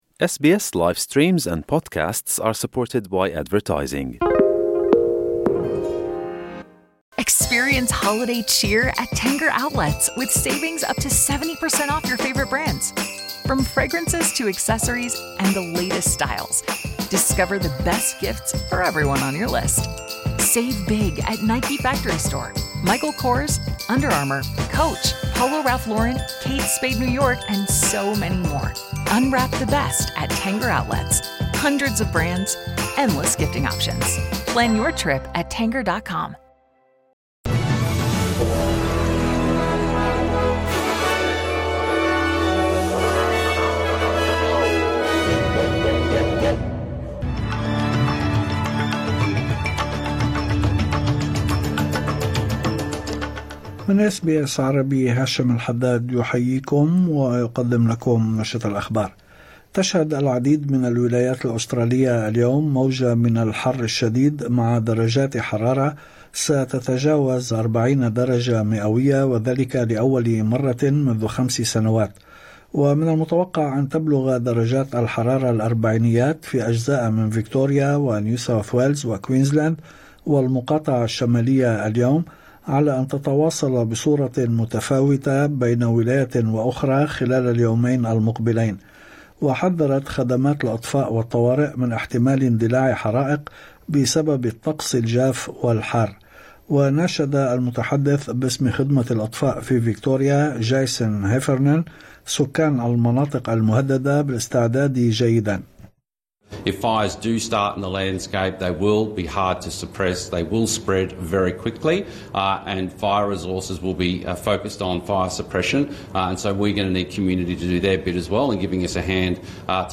نشرة أخبار الظهيرة 16/12/2024